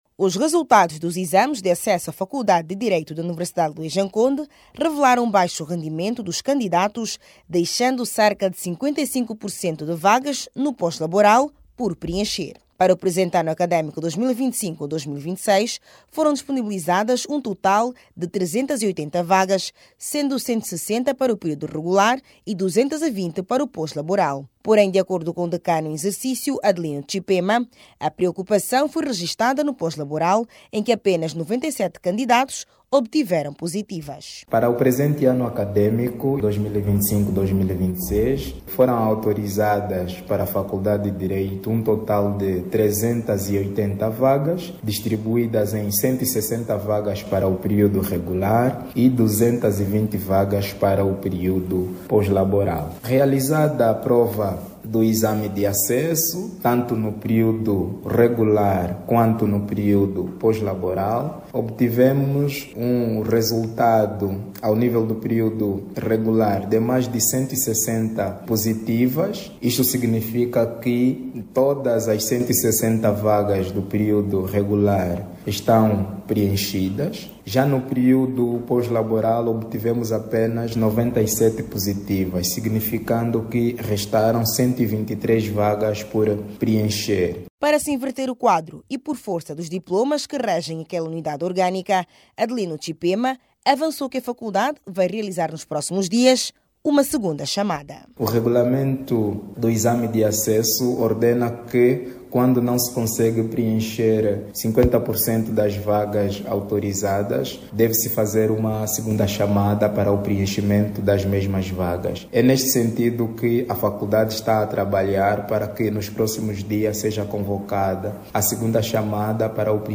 O fraco rendimento, deixa cento e 23 vagas das trezentas e 80 disponíveis, o que irá motivar a realização de uma segunda fase de exames. Saiba mais dados no áudio abaixo com o repórter